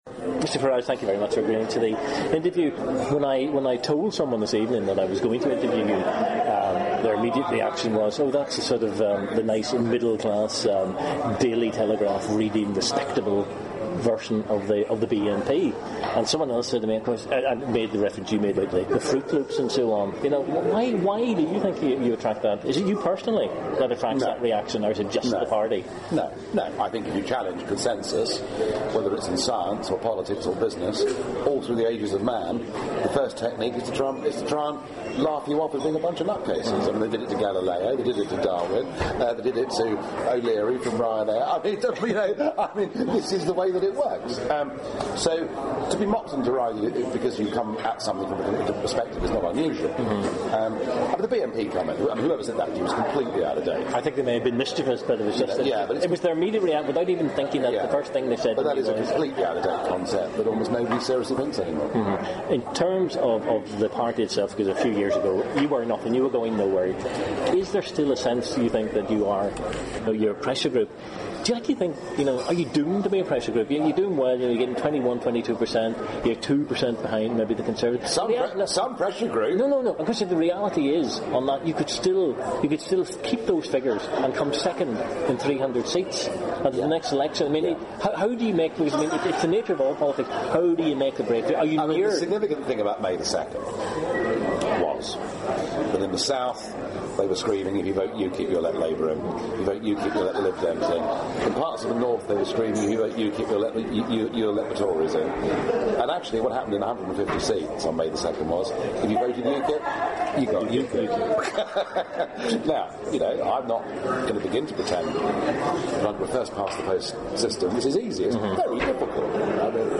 Interview
community radio